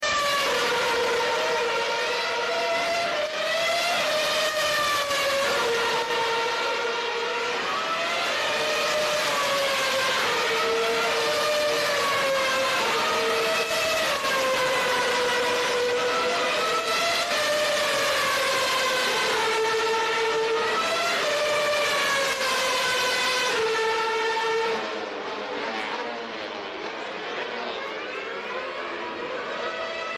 Back when F1 cars gave sound effects free download
Back when F1 cars gave you goosebumps 🏎 If you love engine sounds then you are on right place.